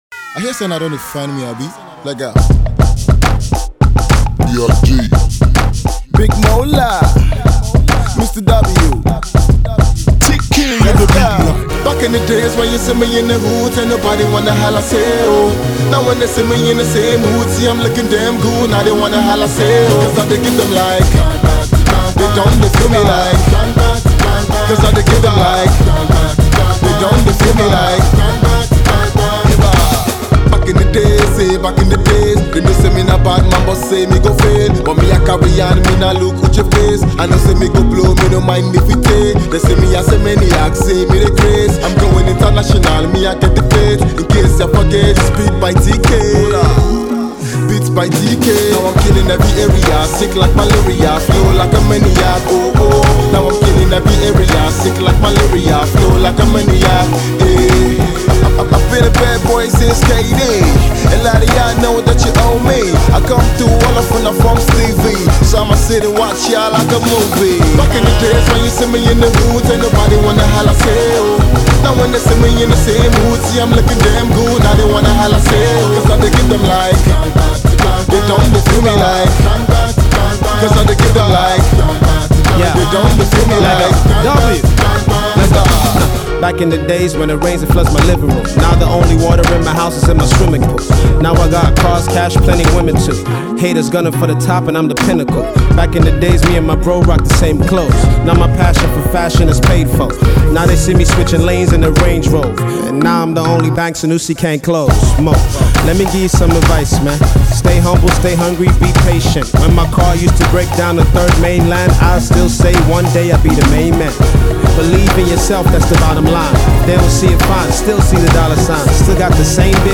rapper
with a hefty rap flow